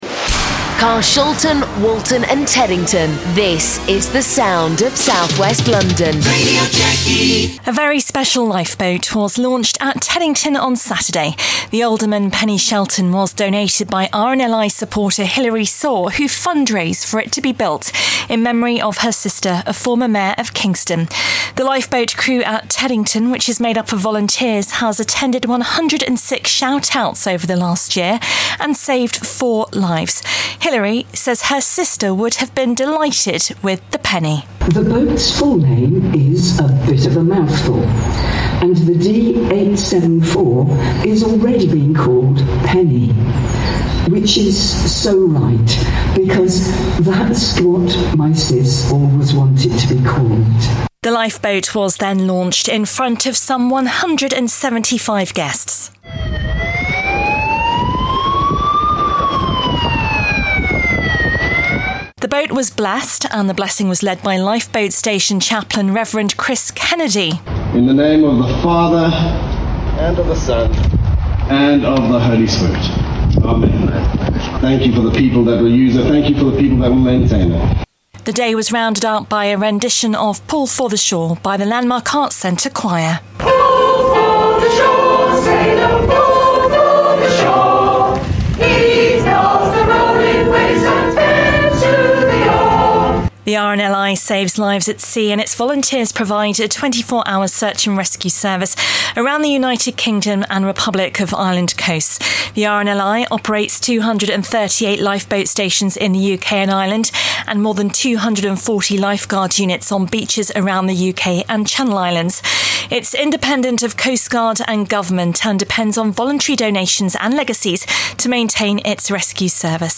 reports from Teddington Lifeboat Station.